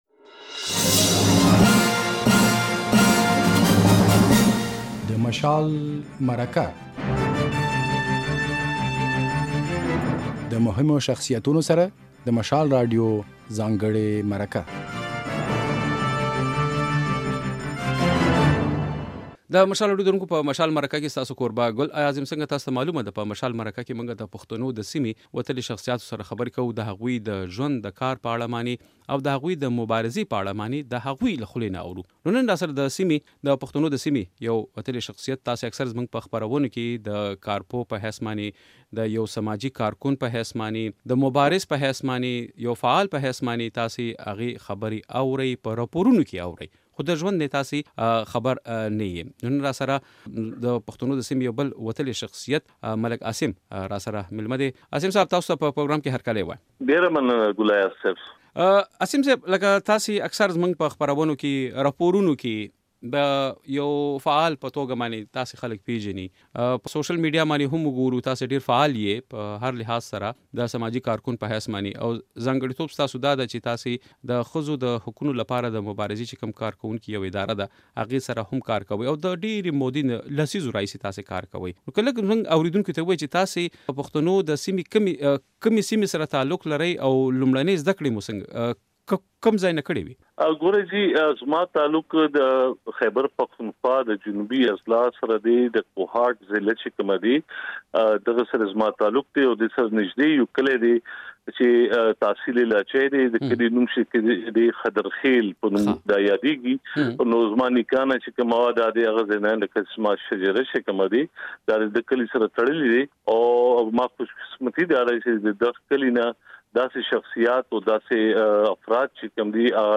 د ده په خبره د ښځو د حقونو په اړه قوانين موجود دي خو عمل نه ورباندې کېږي. بشپړه مرکه واورئ.